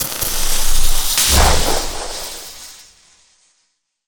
elec_lightning_magic_spell_05.wav